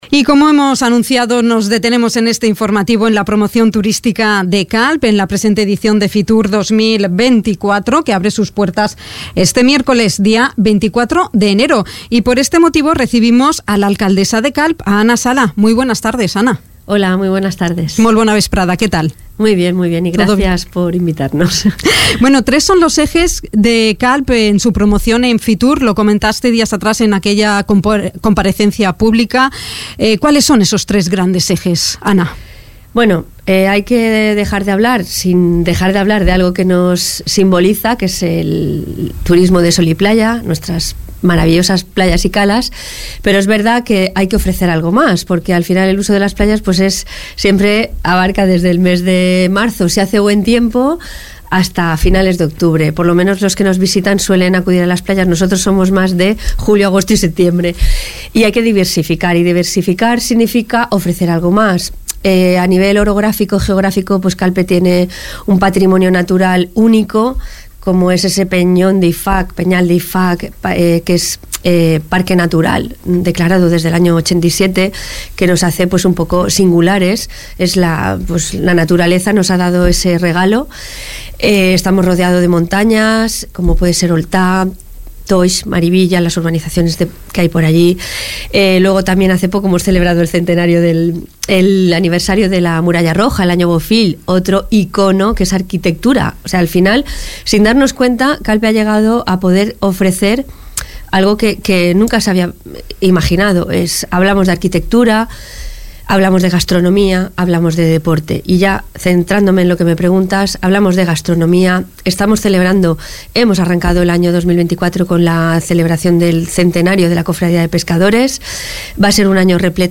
La alcaldesa calpina, Ana Sala ha visitado, hoy lunes 22 de enero, Dénia FM, para profundizar en esta promoción turística de la ciudad del Peñón de Ifach en esta próxima edición de la Feria Internacional de Turismo de Madrid, que abre sus puertas este miércoles 24 de enero.
Entrevista-Ana-Sala-Fitur-.mp3